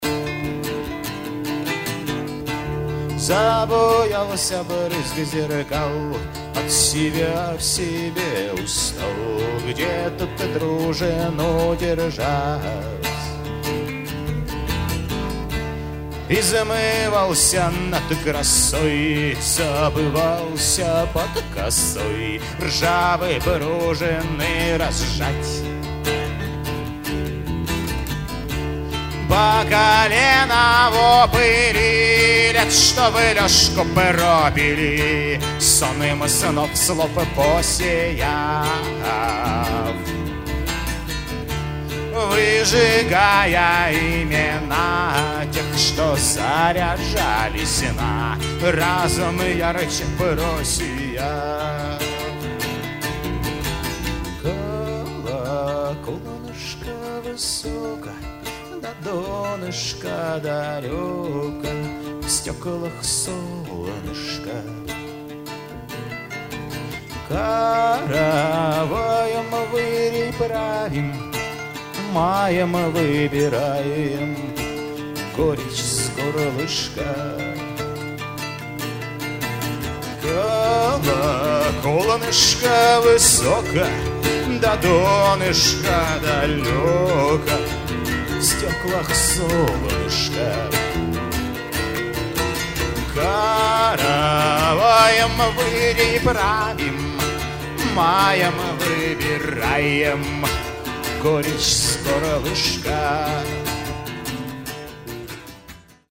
контрабас, бас-гитара
перкуссия
Здесь лежит классический рокенрольный фундамент.
В Бельгии это называют world music.